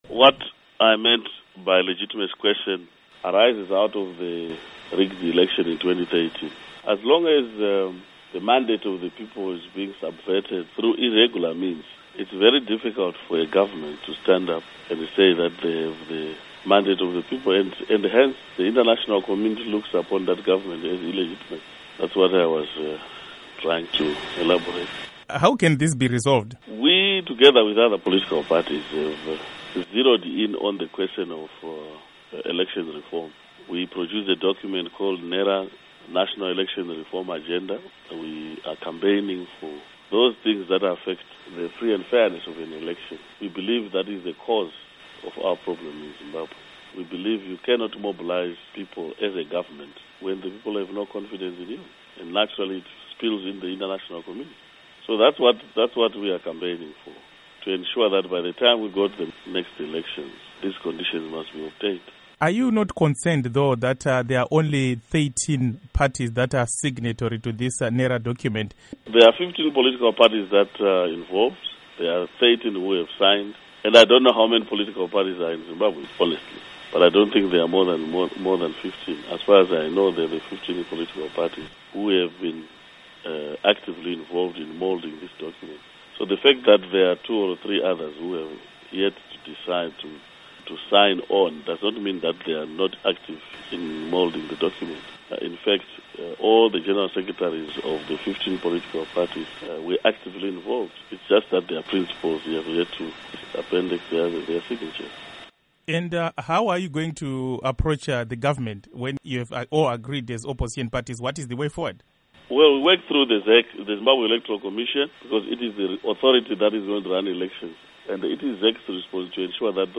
Interview With Morgan Tsvangirai